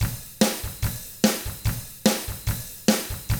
146ROCK T4-L.wav